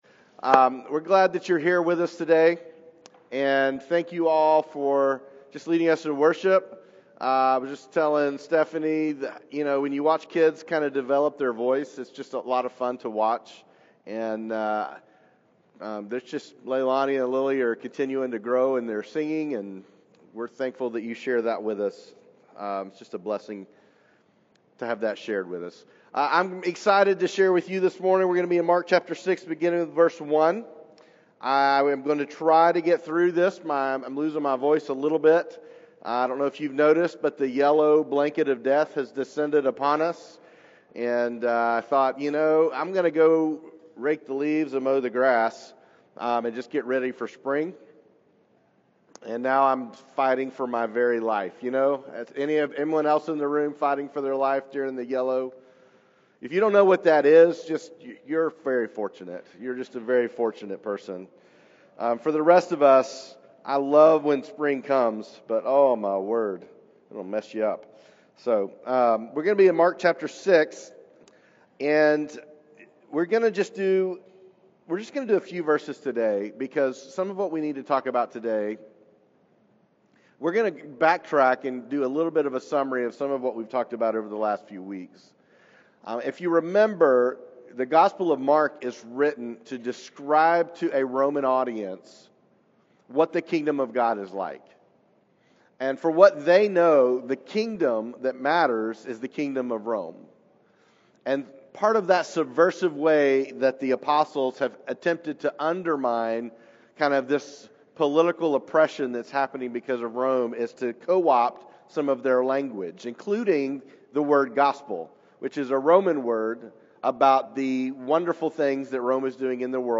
Weekly teaching of Journey Church: A faith community in Chattanooga devoted to making Jesus famous and healing the brokenhearted.